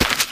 STEPS Newspaper, Walk 05.wav